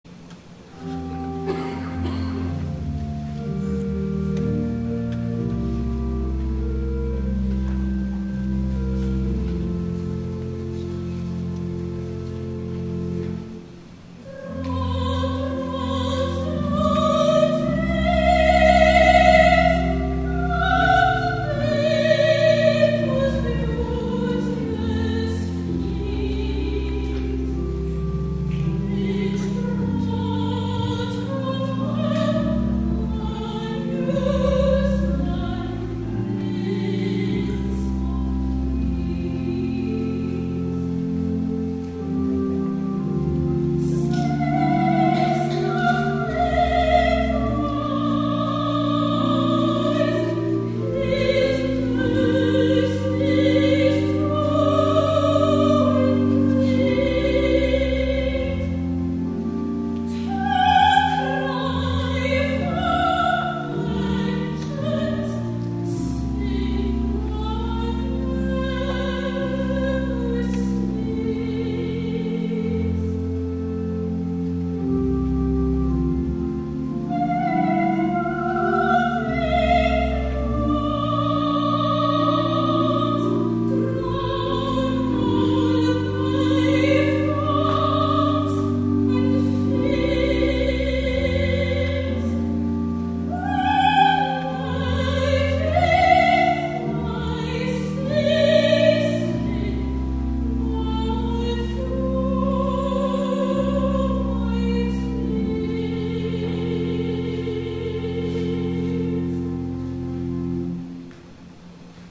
THE ANTHEM
soloist